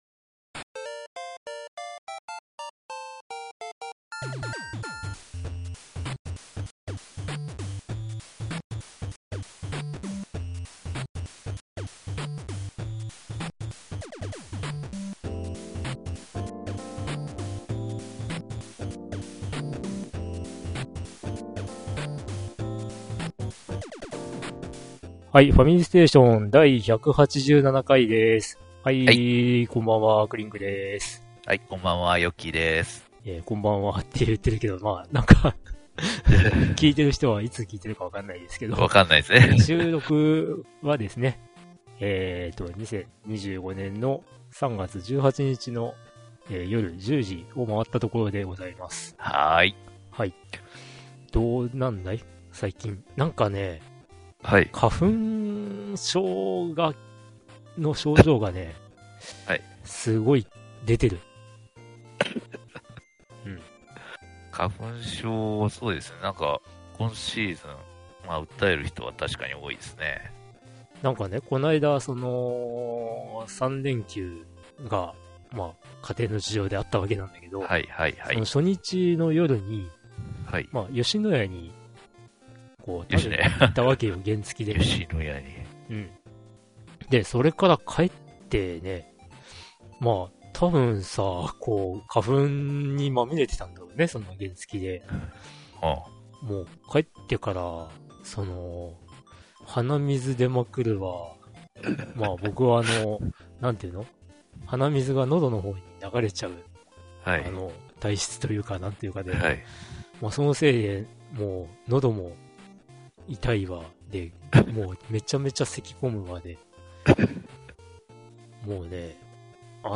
ファミリーステーション(略:ファミステ)とは、リスナーからのお便りを元に昔懐かしいゲームや最近のゲーム、世間話などを語る、ゲーム系インターネットラジオ番組です。
不定期にゲスト(という呼び名の友人たち)も登場します。地方のネタが出たり、まったりした雰囲気の番組ですが、多くの方が楽しんで聞いてもらえれば幸いです。